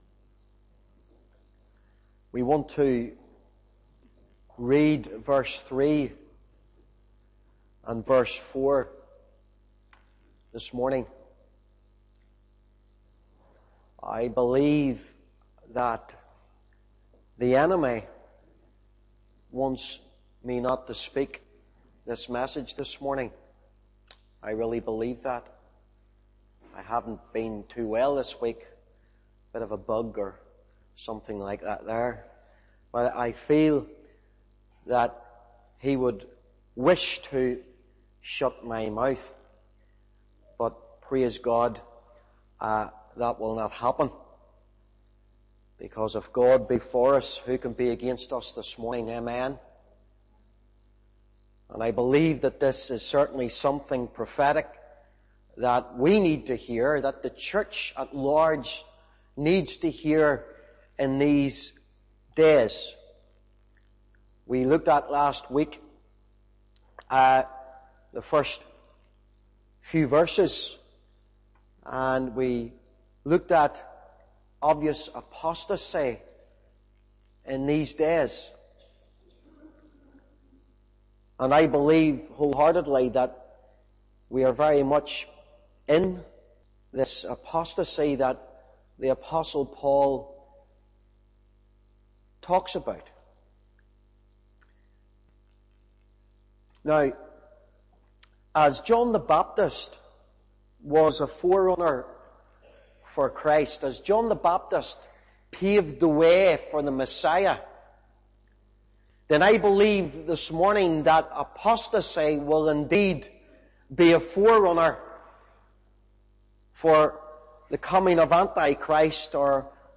In this sermon, the apostle Paul is emphasizing the importance of using spiritual gifts and the teaching of God's word to mature in faith. He warns against being easily swayed by false doctrines, comparing it to the way lice crawl from one head to another.